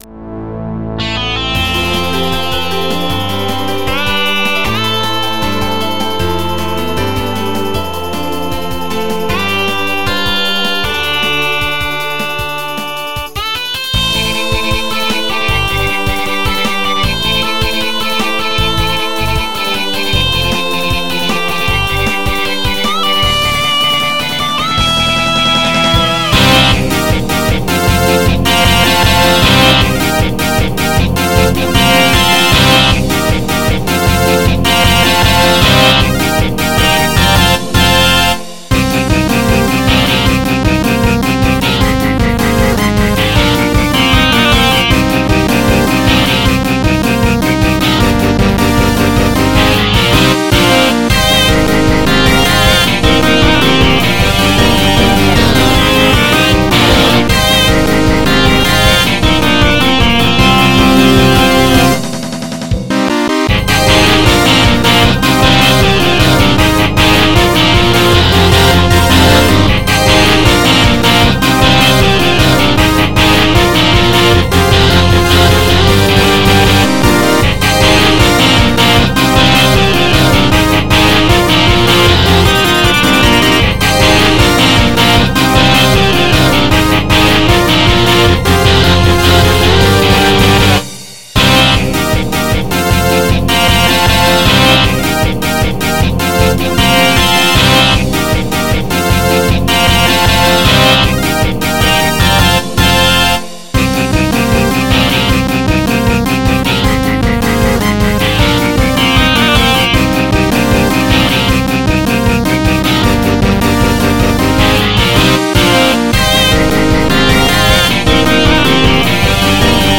MIDI 170.94 KB MP3